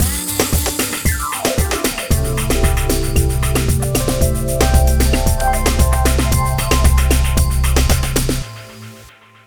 Ala Brzl 2 Full Mix 1b-C.wav